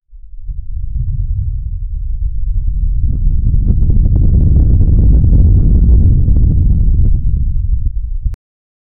A deep rumbling thunderstorm rolling across the sky.
a-deep-rumbling-thunderstorm-rolling-across-the-sky-ogz6lfvp.wav